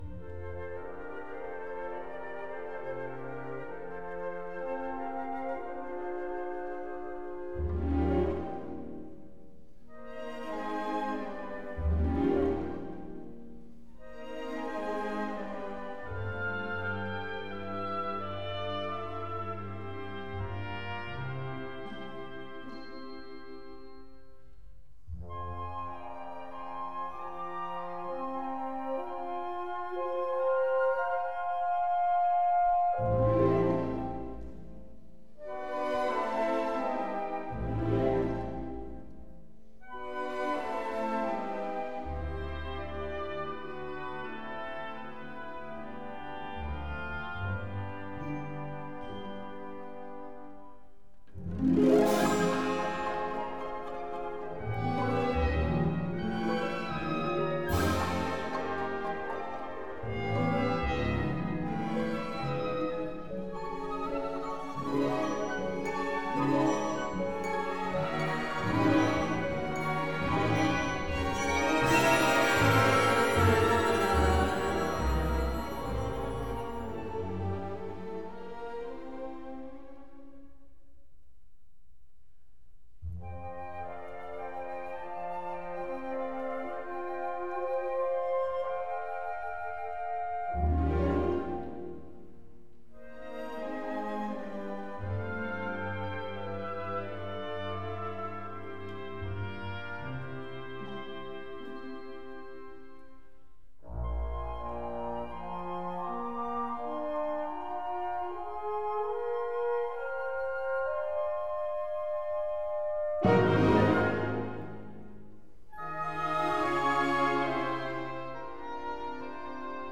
by Montreal Symphony Orchestra and Chorus; Charles Dutoit | Ravel: Daphnis et Chloé